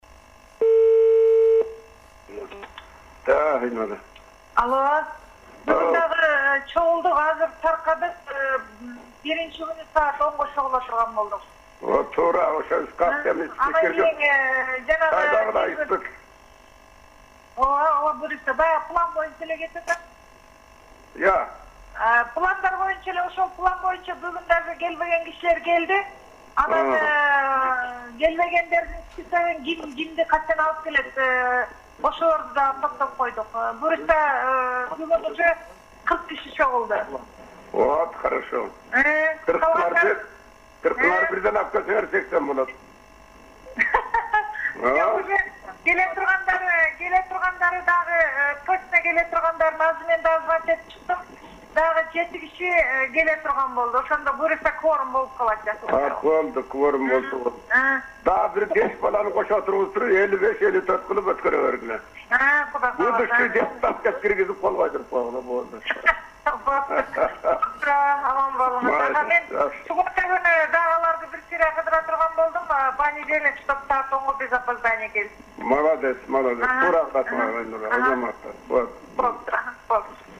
аял менен сүйлөшүү